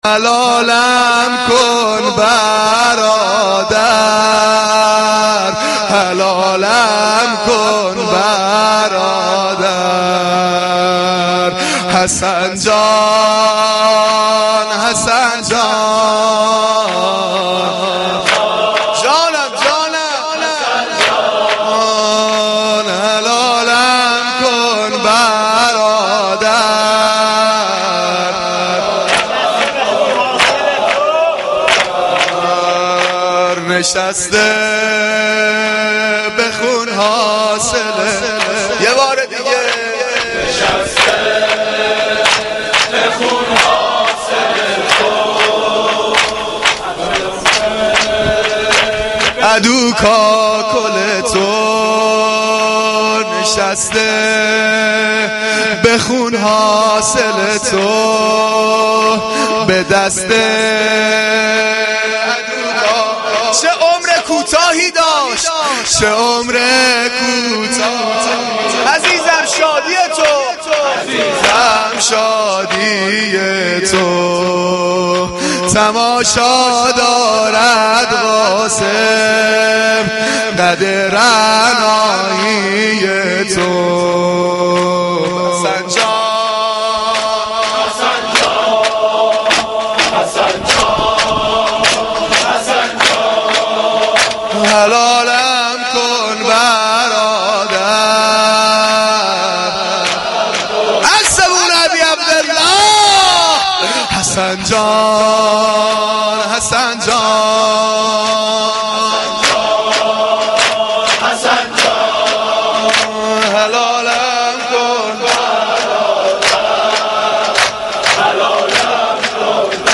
مداحی
Shab-6-Moharam-4.mp3